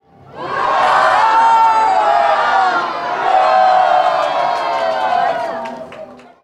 Яркие и радостные возгласы толпы идеально подойдут для монтажа видео, создания праздничных роликов, поздравительных открыток или использования в проектах.
Крики Ура толпы